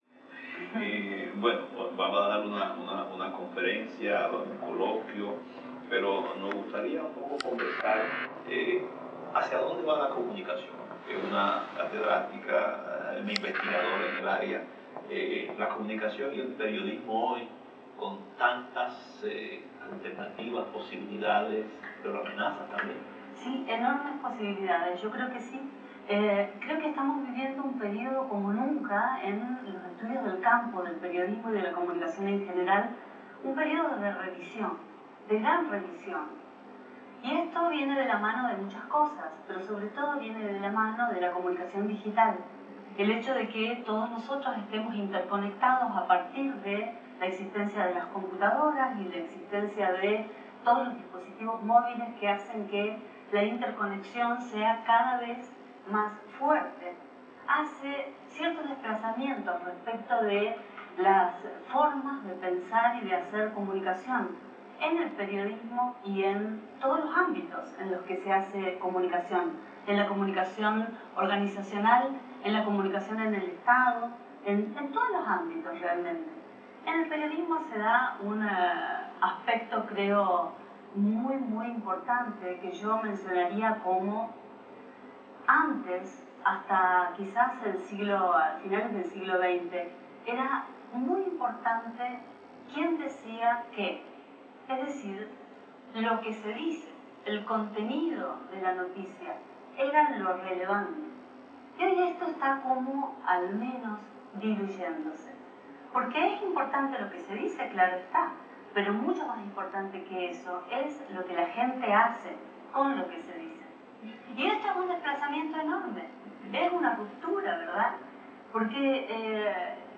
Comparto una entrevista que me realizaran en la Televisión Dominicana sobre el nuevo periodismo